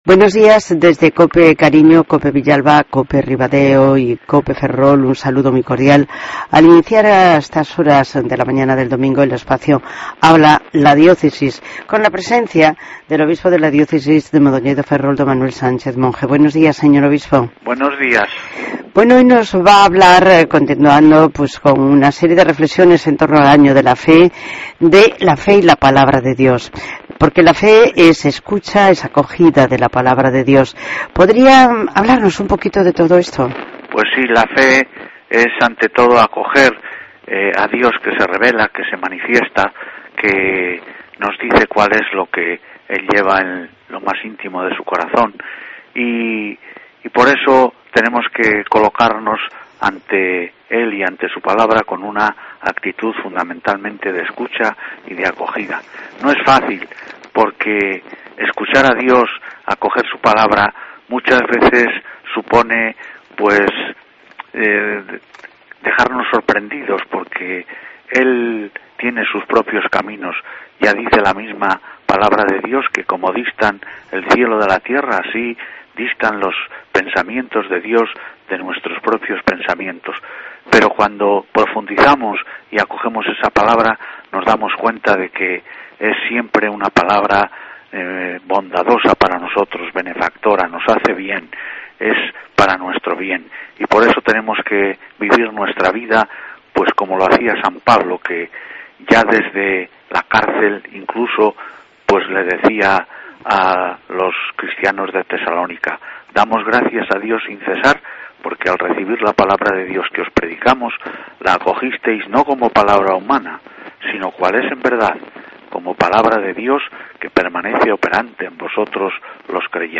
Redacción digital Madrid - Publicado el 20 ene 2013, 13:26 - Actualizado 14 mar 2023, 16:02 1 min lectura Descargar Facebook Twitter Whatsapp Telegram Enviar por email Copiar enlace Tema: La fe y la Palabra de Dios, es el tema sobre el que nos habla el Obispo de la Diócesis de Mondoñedo-Ferrol, D. Manuel Sánchez Monge.